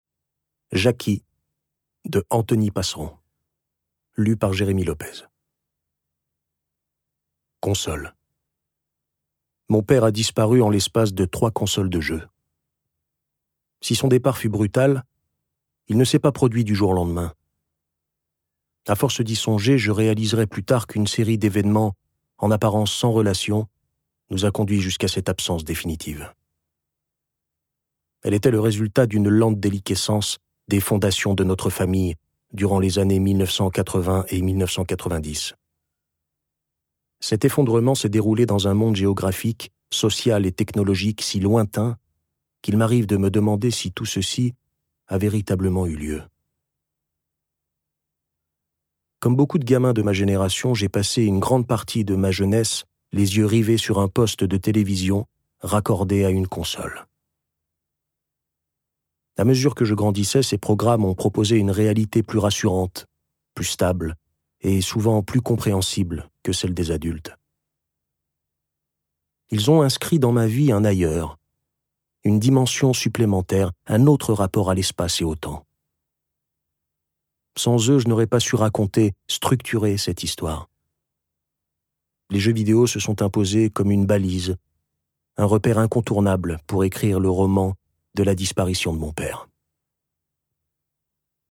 Diffusion distribution ebook et livre audio - Catalogue livres numériques
Un roman d’apprentissage en trois consoles, de la tendresse de l’enfance aux désillusions de l’adolescence. Interprétation humaine Durée : 03H29 19 , 45 € Ce livre est accessible aux handicaps Voir les informations d'accessibilité